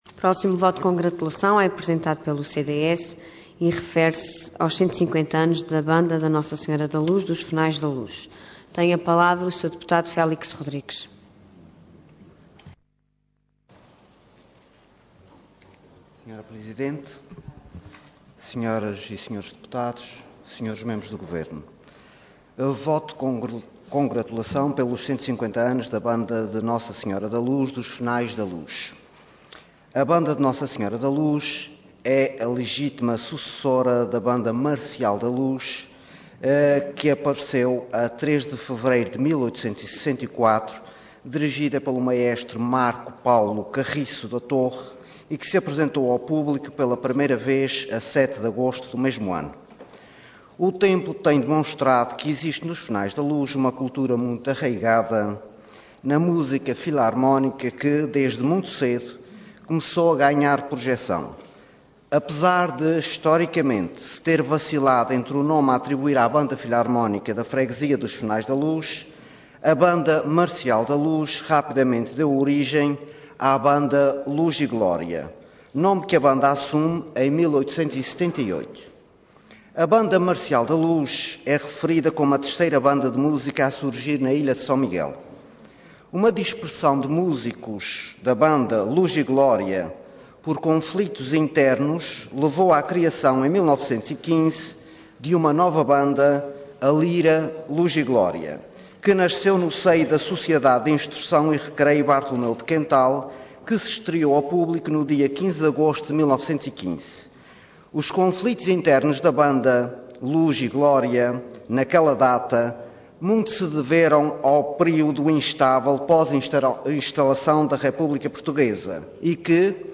Intervenção Voto de Congratulação Orador Félix Rodrigues Cargo Deputado